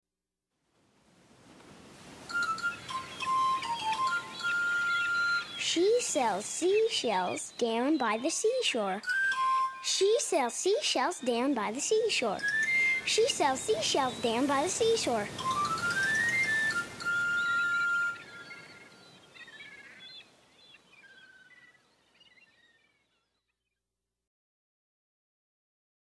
英语童谣